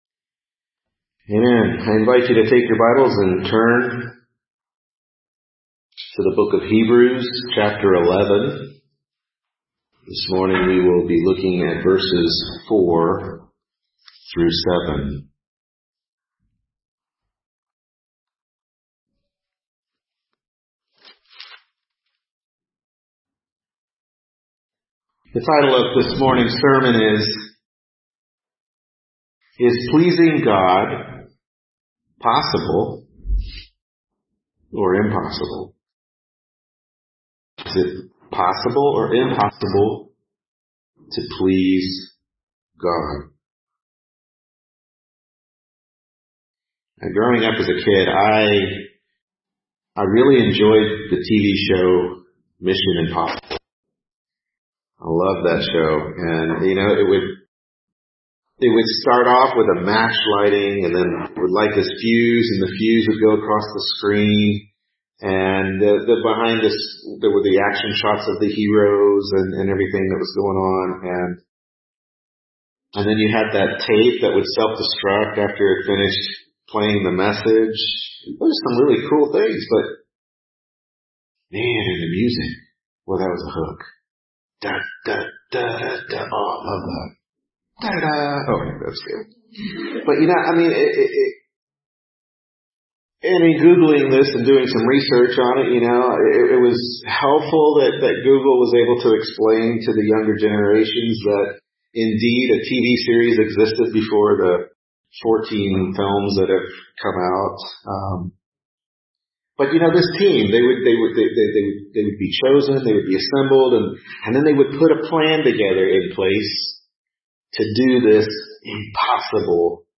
Hebrews 11:4-7 Service Type: Morning Worship Service Hebrews 11:4-7 Is Pleasing God Possible or Impossible?